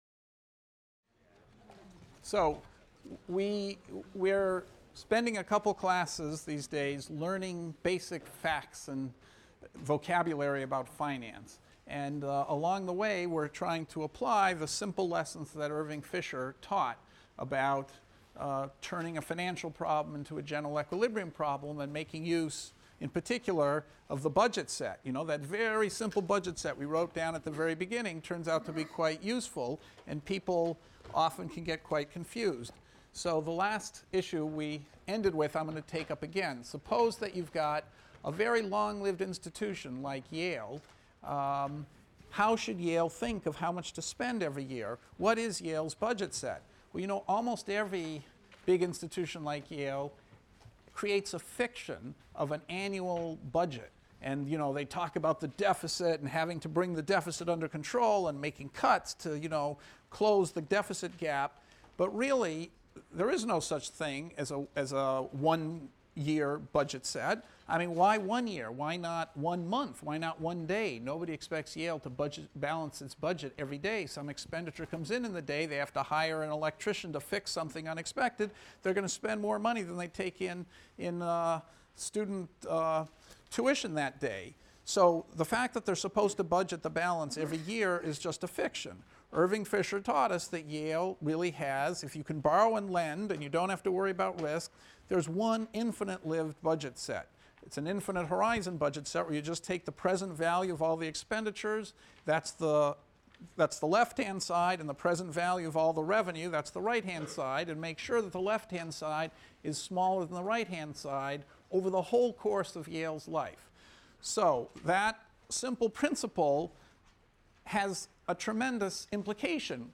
ECON 251 - Lecture 8 - How a Long-Lived Institution Figures an Annual Budget; Yield | Open Yale Courses